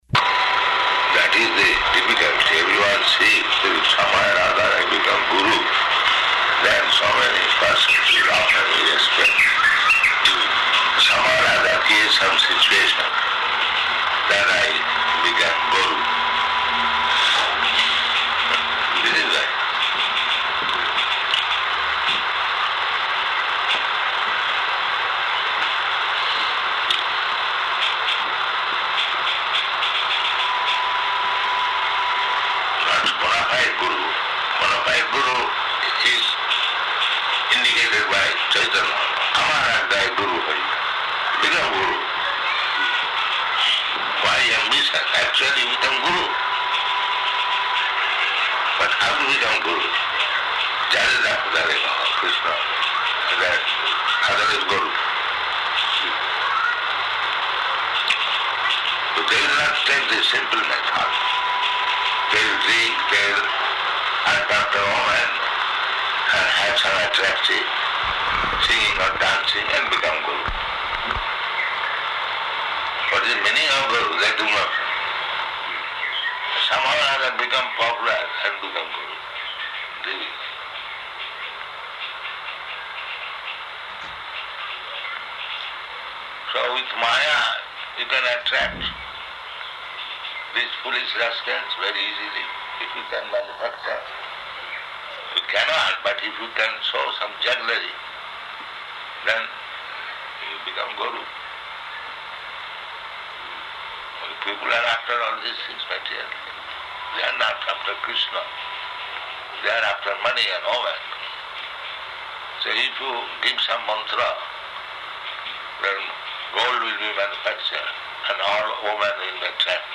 Room Conversation